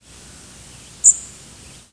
Louisiana Waterthrush Seiurus motacilla
Flight call description A sweet, trilled "dzeedt", often with a subtle rise.
Perched bird on the breeding grounds.
Similar species Has the same sweetness of Blackburnian and Bay-breasted Warblers, but is typically longer.
The frequency track was single or double-banded and is level or slightly rising.